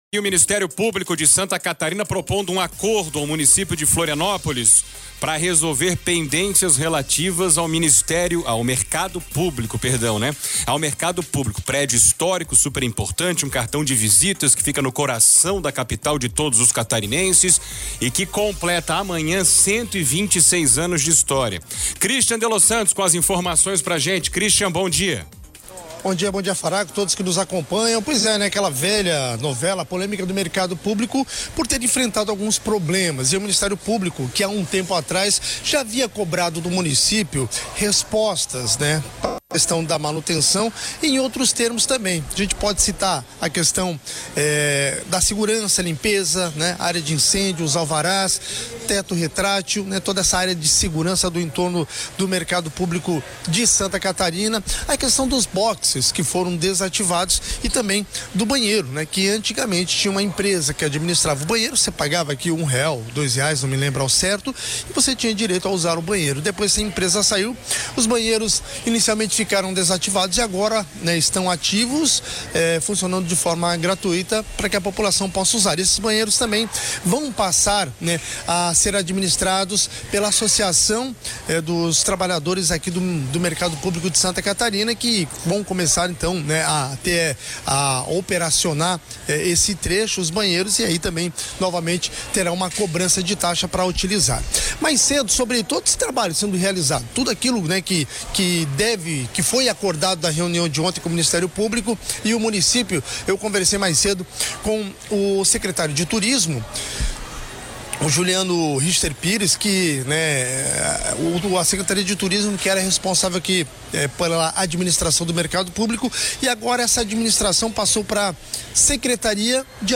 O secretário de Desenvolvimento Econômico, Juliano Richter Pires, e a secretária municipal de Administração, Cynthia Camargo d’Ivanenko Vahl, participaram do programa Notícias da Manhã desta terça-feira (4), na CBN Floripa, e falaram sobre o assunto.